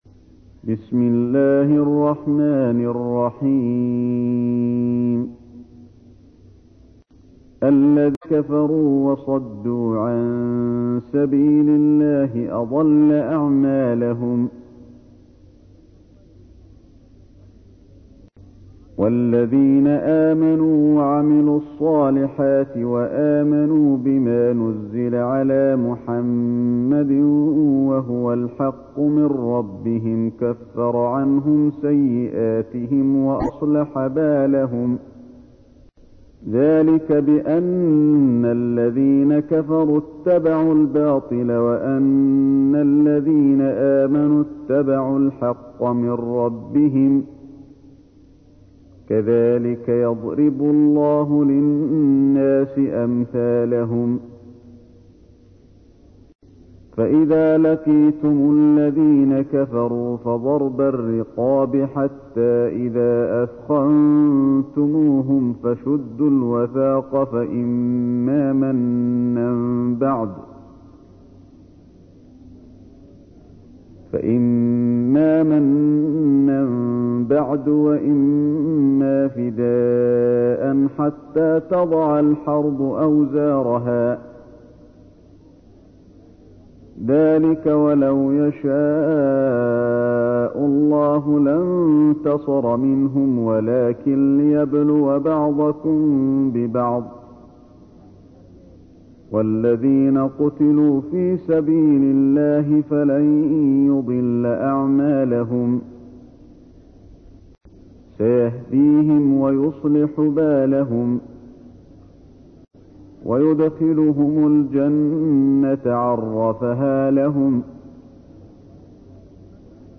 تحميل : 47. سورة محمد / القارئ علي الحذيفي / القرآن الكريم / موقع يا حسين